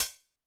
Drums_K4(09).wav